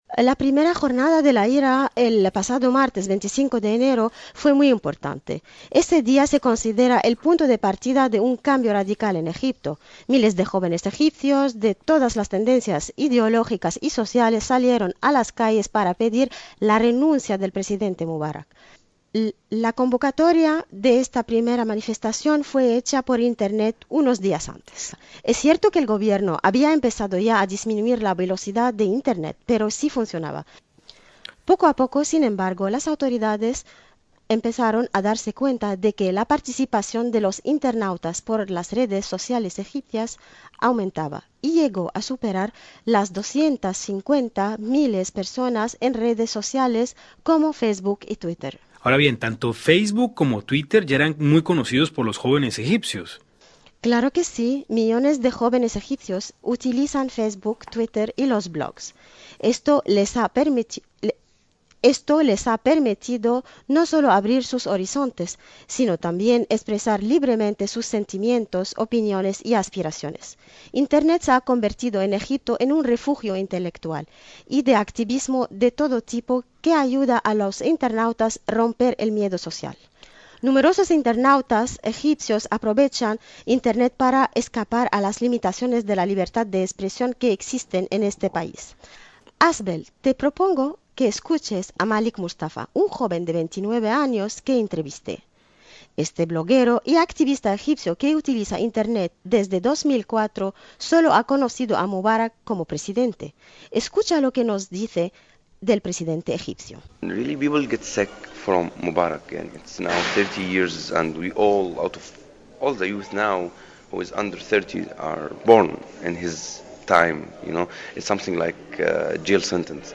Las redes sociales han tenido una actuación fundamental como instrumentos de comunicación en país africano. Escuche el informe de Radio Francia Internacional.